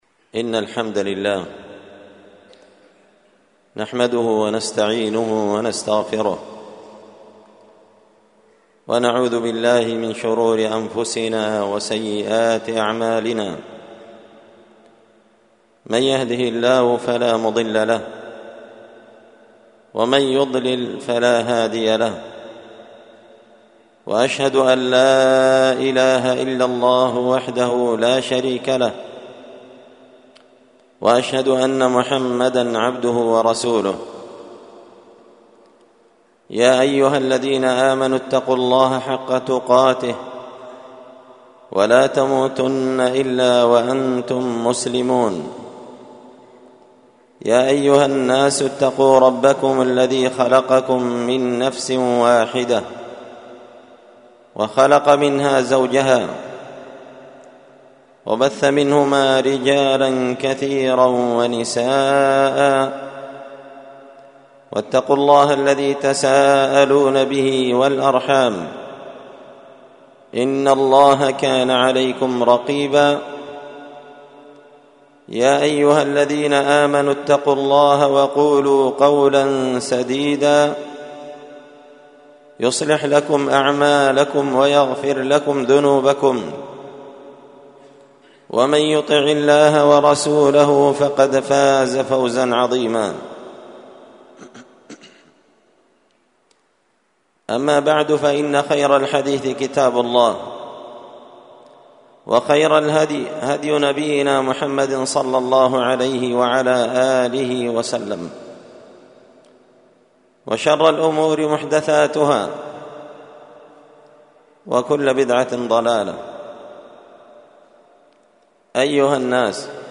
خطبة جمعة بعنوان
ألقيت هذه الخطبة بدار الحديث السلفية بمسجد الفرقان قشن-المهرة-اليمن تحميل…